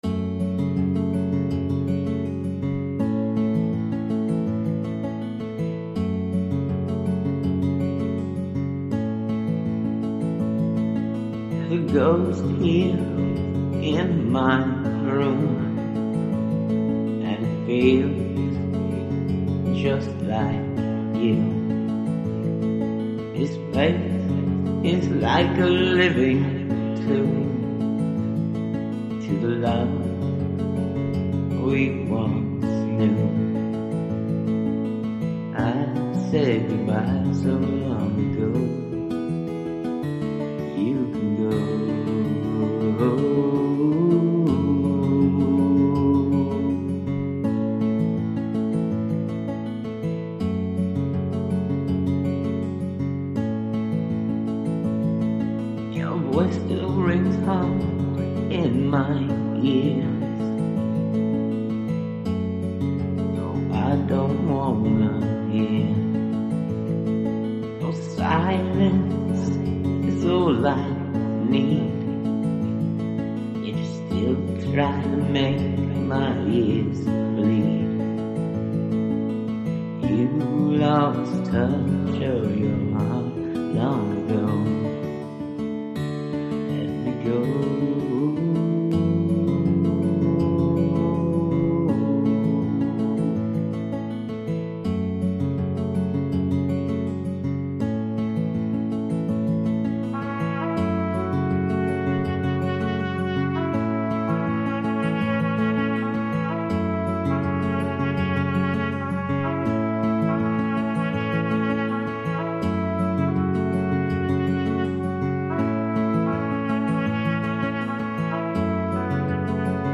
Really lovely fingerpicking, and it sets the perfect mood for the song!
Your voice and the instrumental/music is haunting and fits the words perfectly.
The dark brooding melody is perfect!